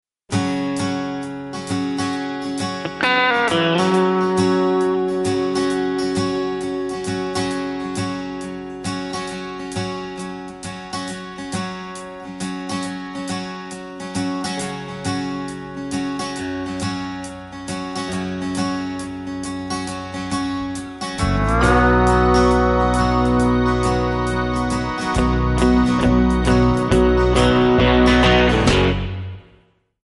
F#
MPEG 1 Layer 3 (Stereo)
Backing track Karaoke
Country, 1990s